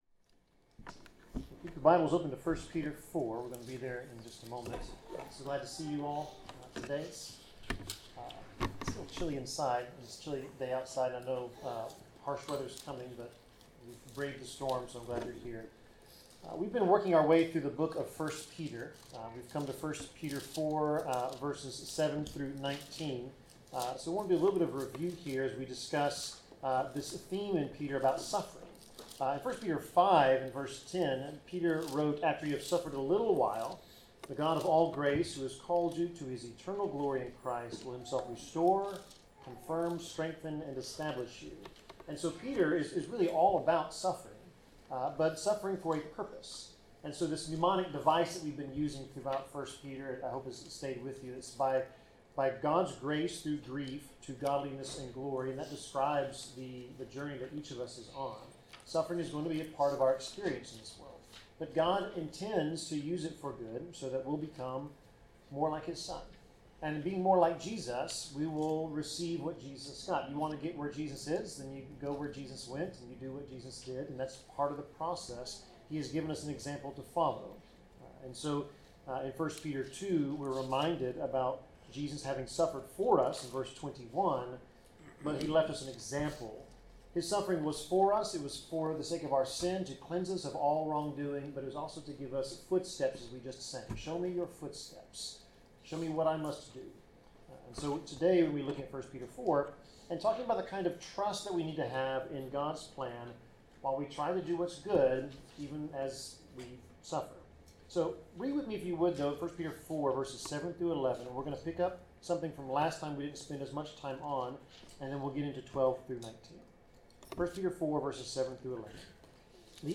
Passage: 1 Peter 4:7-19 Service Type: Sermon